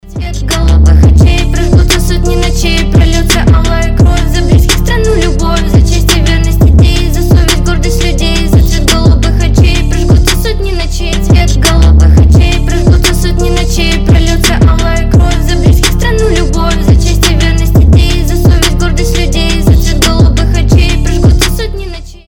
• Качество: 320, Stereo
лирика
грустные
басы
красивый женский голос
ремиксы